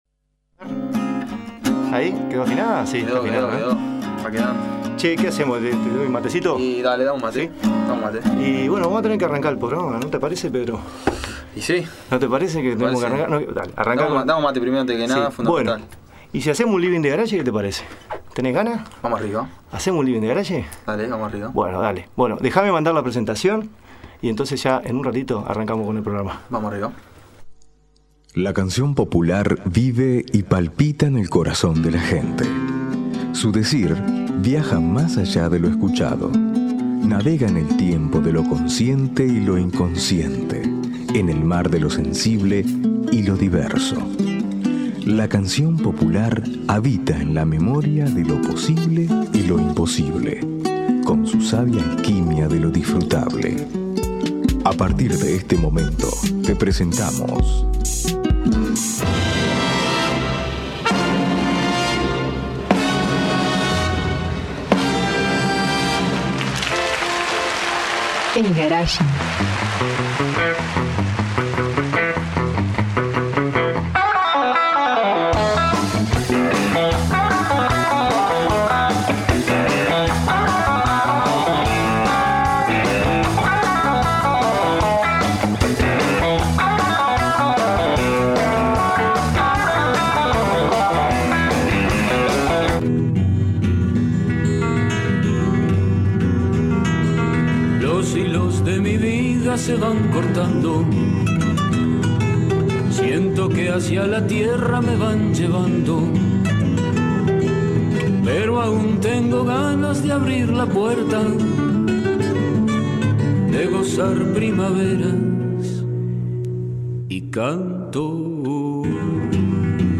Entrevista programa El Garage. Uni Radio.